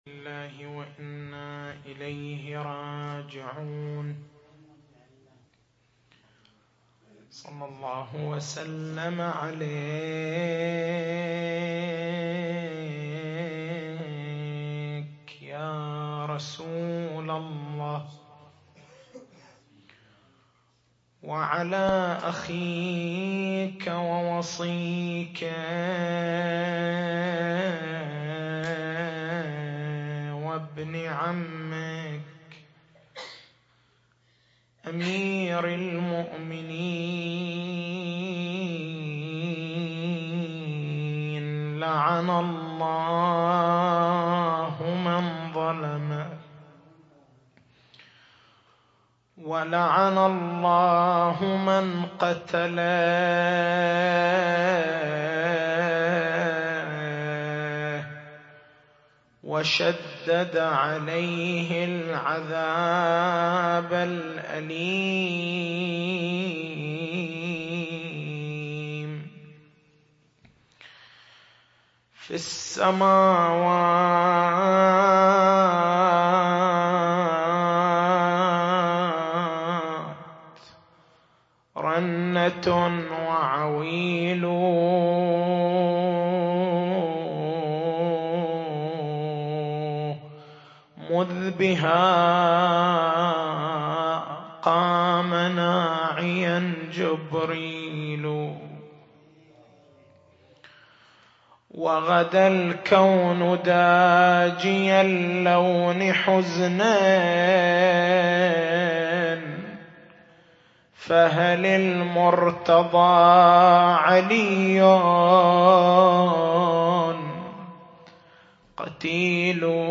تاريخ المحاضرة: 22/09/1426 نقاط البحث: حقيقة الإمامة هل الإمامة منصب أصيل أم نيابي؟